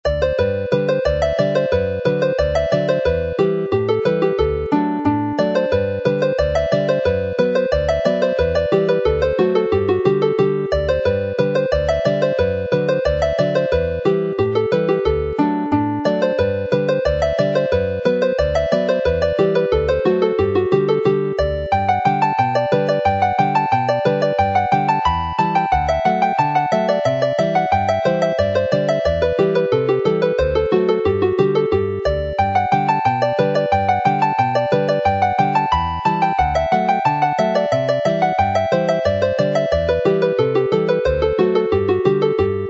often played gently, but faster here.